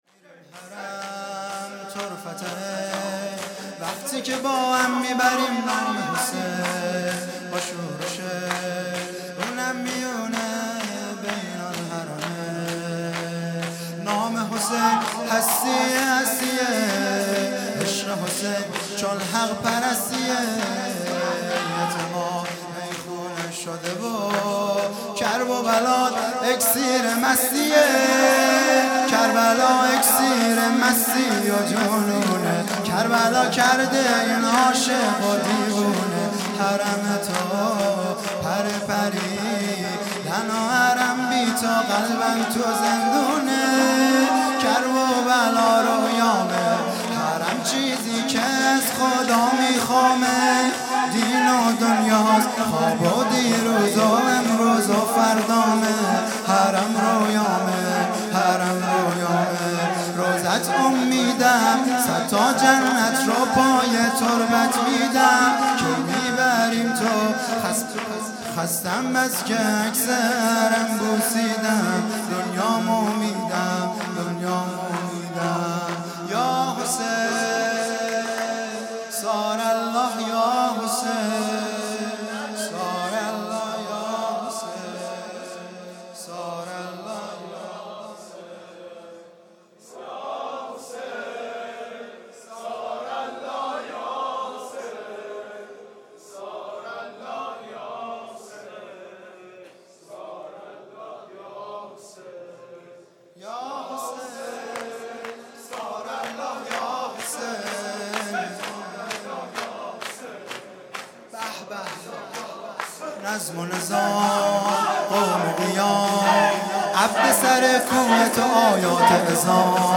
شور
اکسیر مستی|جلسه هفتگی ۲۹ فروردین ۹۶